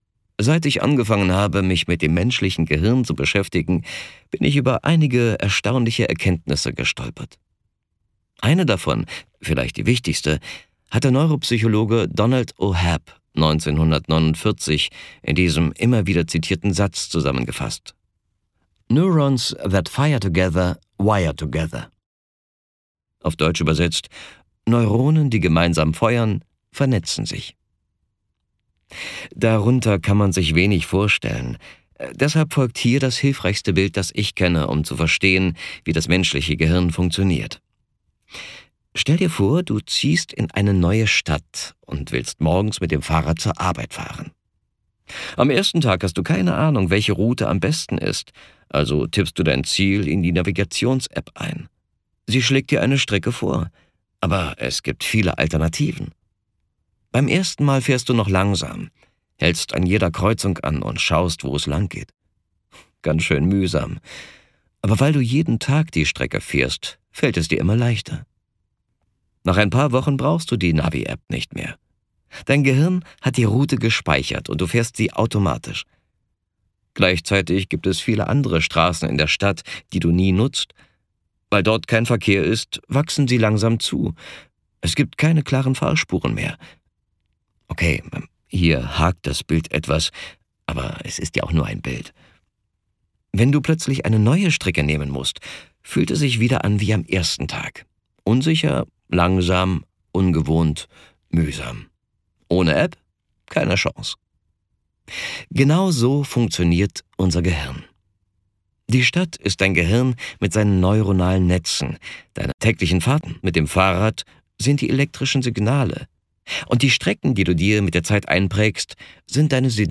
Zu diesem Hörbuch gibt es Zusatzmaterial.
Gekürzt Autorisierte, d.h. von Autor:innen und / oder Verlagen freigegebene, bearbeitete Fassung.